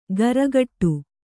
♪ garagaṭṭu